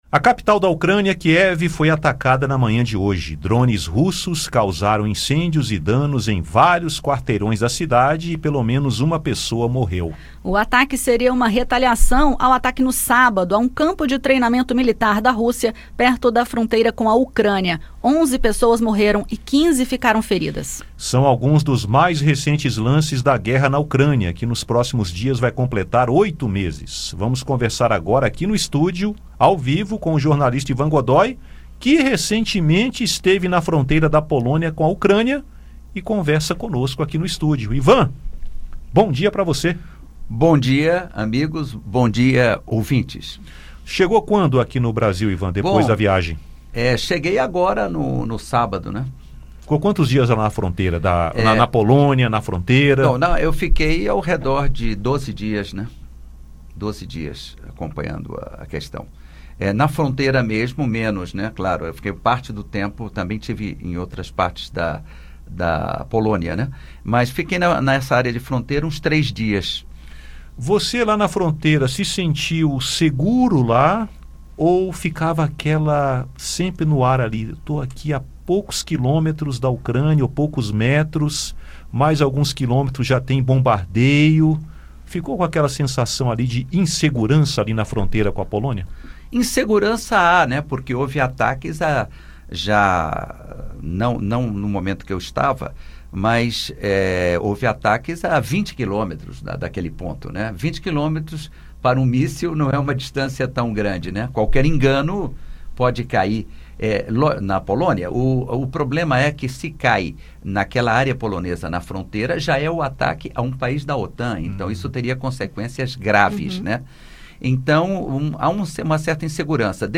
Na manhã desta segunda-feira (17), a capital da Ucrânia, Kiev, foi atacada por drones russos, causando incêndios e danos. Esta seria uma retaliação ao ataque, no sábado (15), a um campo de treinamento militar da Rússia perto da fronteira com a Ucrânia, no qual 11 pessoas morreram e 15 ficaram feridas. O jornalista e analista internacional